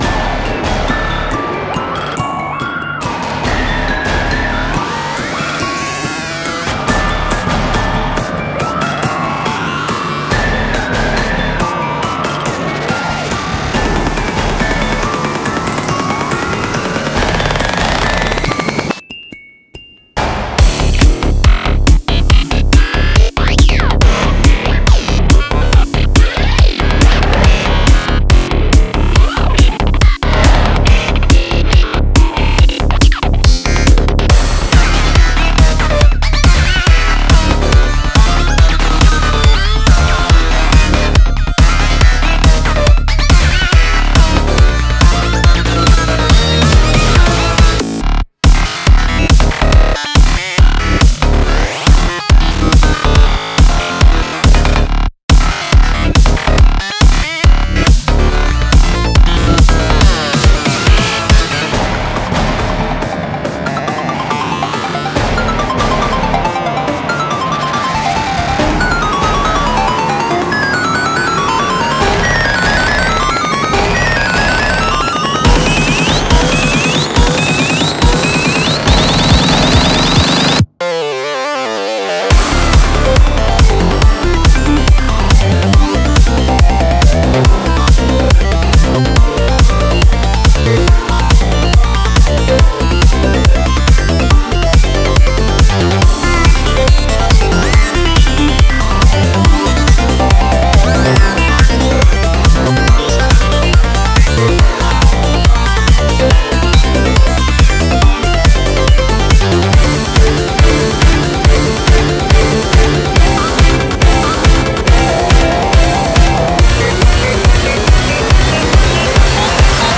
BPM140
Audio QualityPerfect (High Quality)
Comments[PSY-GLITCH]